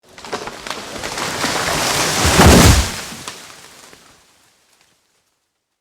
Tree Fall 4
Tree Fall 4.mp3